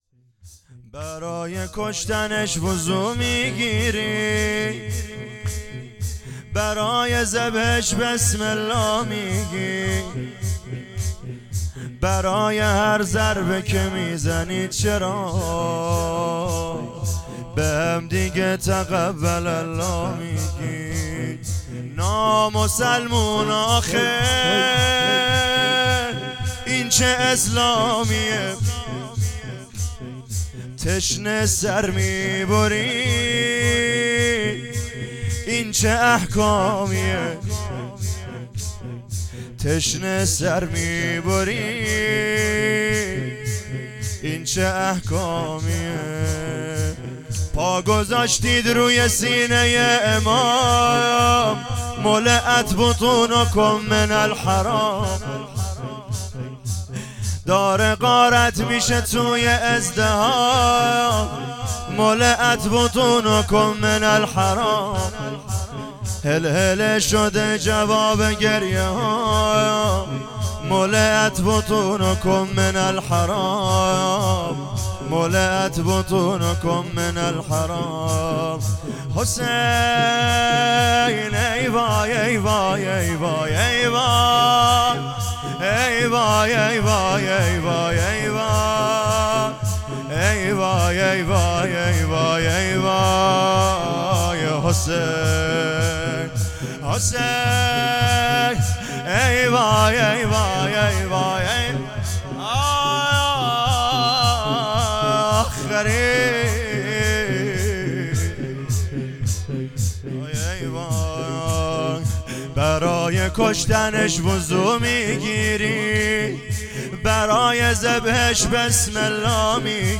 0 0 زمینه شب اول محرم